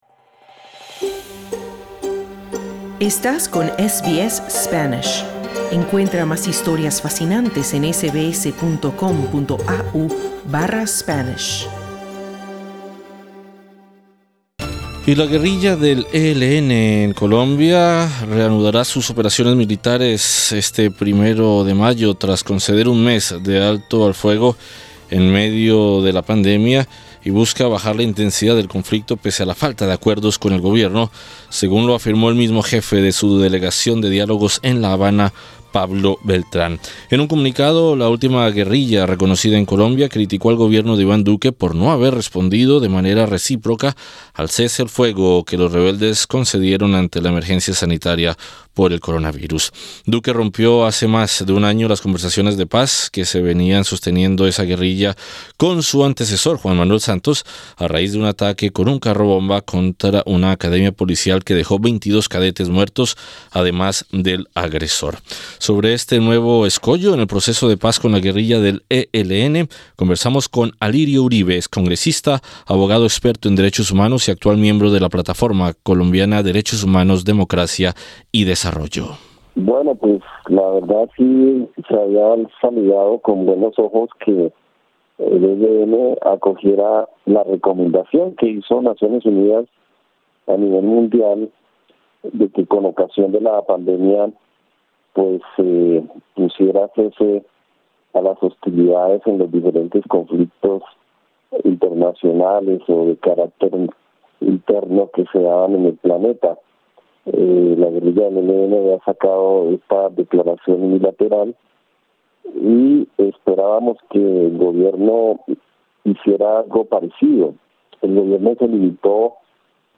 Sobre este nuevo escollo en el proceso de paz con la guerrilla del ELN, conversamos con Alirio Uribe, excongresista, abogado experto en Derechos Humanos y actual miembro de la Plataforma Colombiana Derechos Humanos, Democracia y Desarrollo.